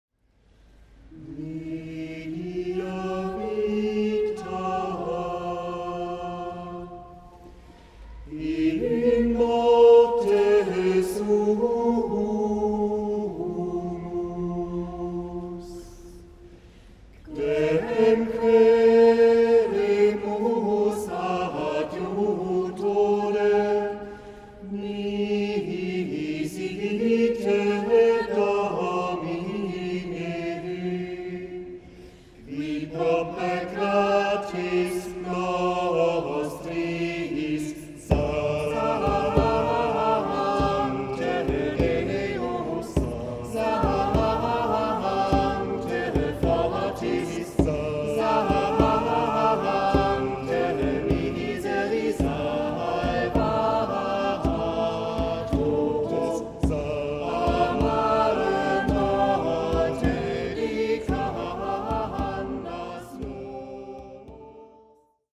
Die Hörproben stammen von Livemitschnitten in der Kieler St. Nicolai Kirche aus dem Jahr 2008 und 2009.